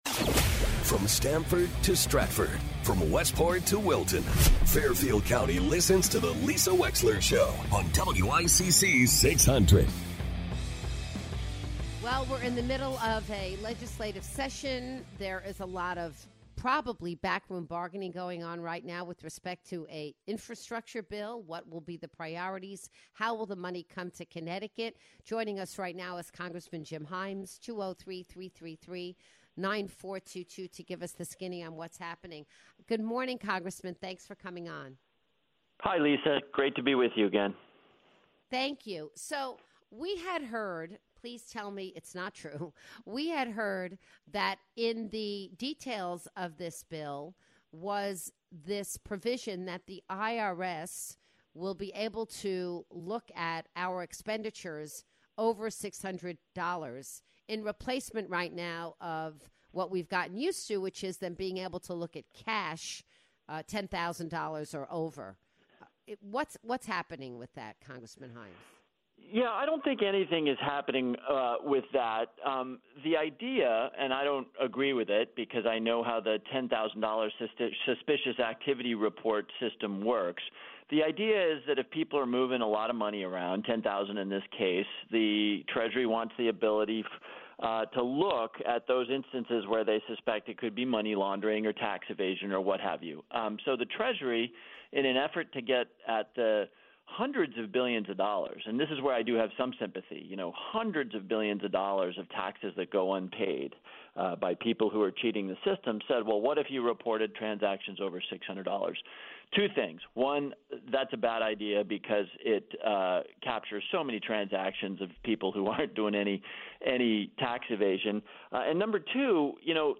Congressman Jim Himes calls into the show to discuss the massive Infrastructure Bill making it's way through the Capitol.